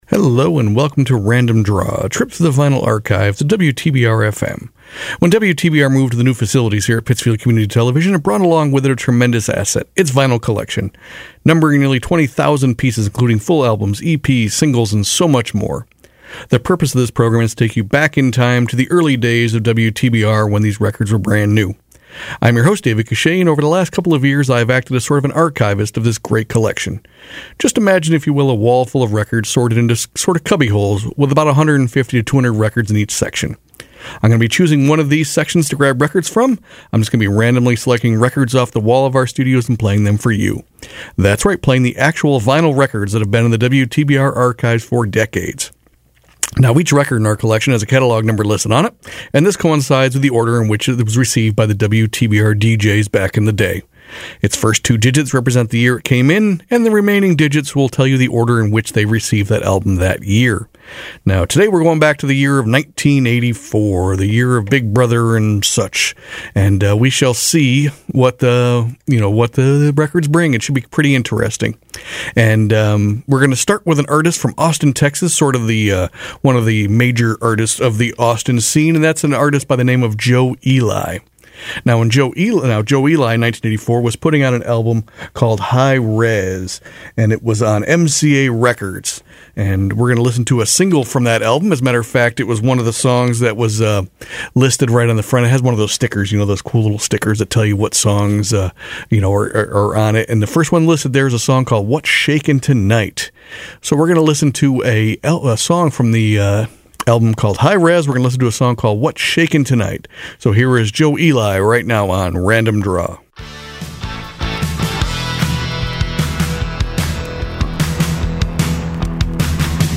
This week Big Brother might be listening as we travel back to the year 1984 and listen to some randomly chosen tunes from our vinyl archives.